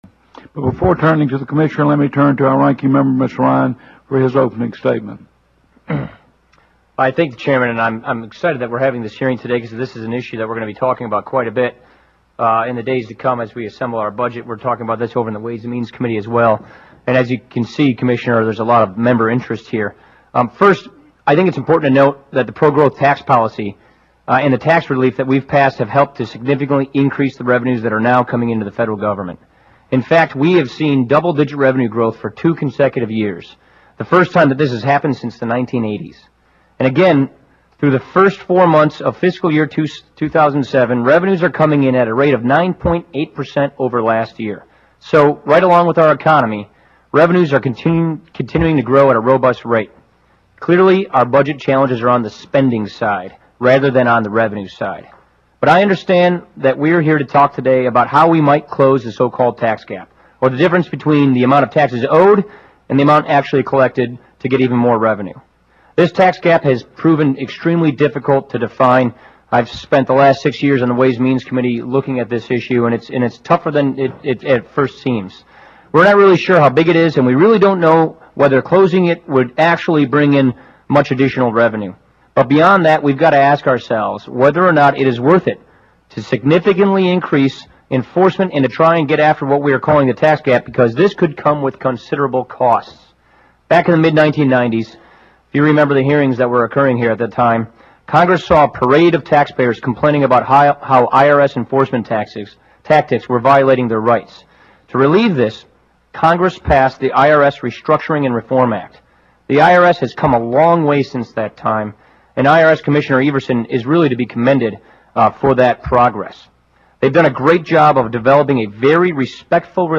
Opening Statement - Ranking Member: Paul Ryan:
House_Budget_Ryan_Opening.mp3